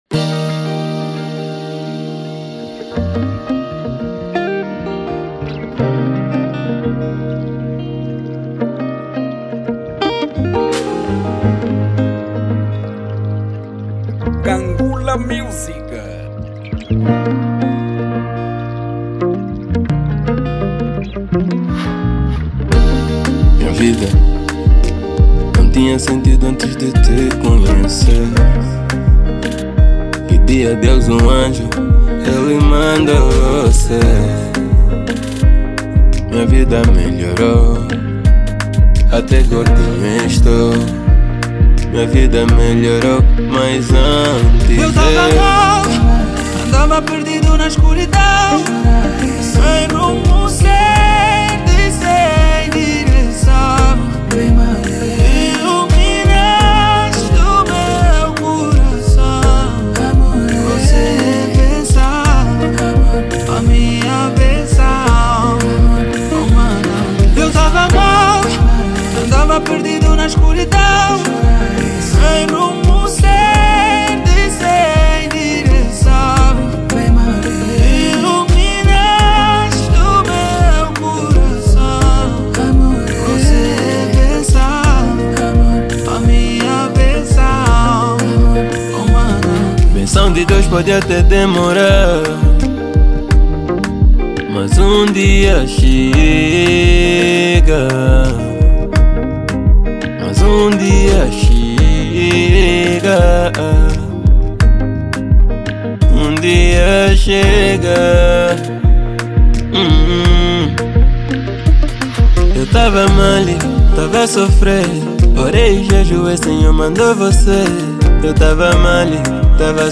| Zouk